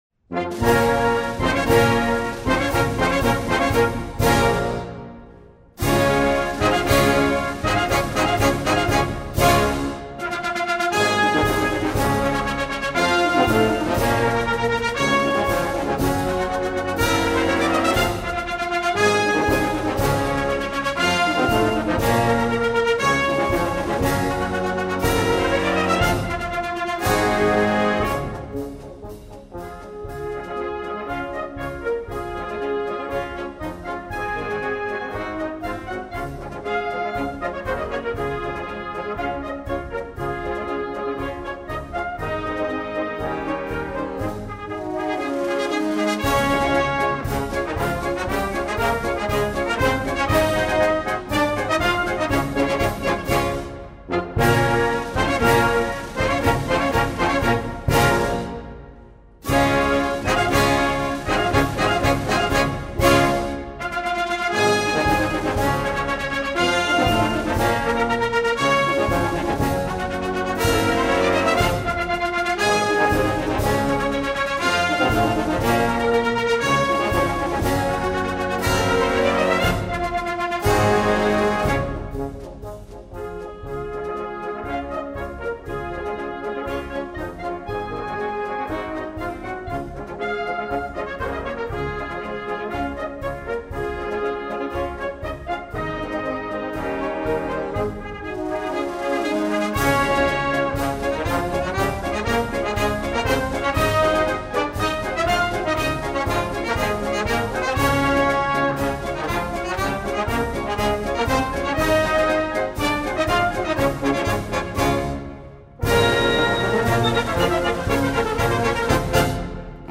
Band → Concert Marches
Voicing: Concert March